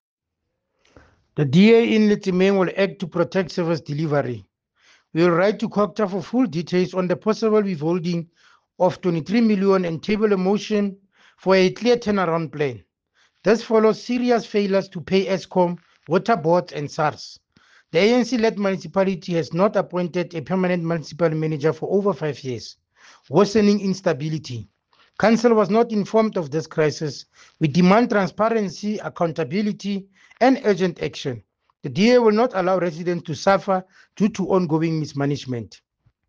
Sesotho soundbites by Cllr Thabo Nthapo and